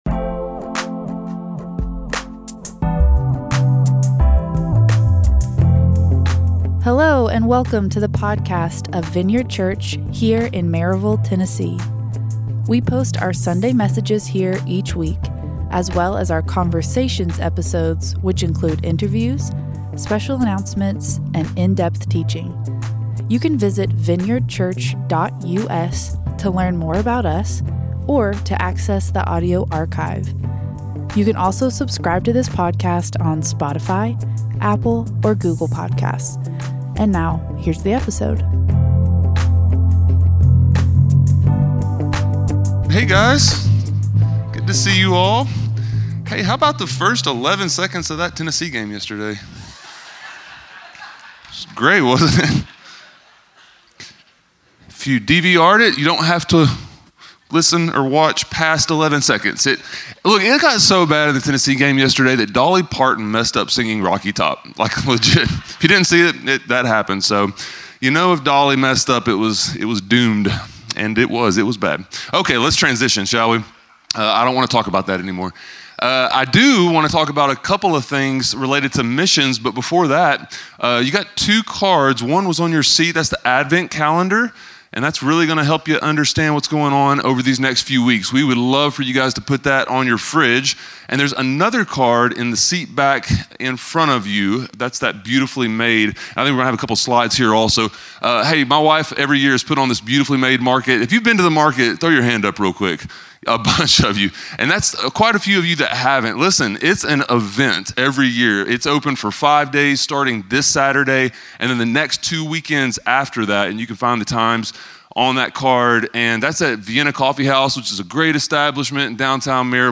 A message from the series "Seeds and Harvest."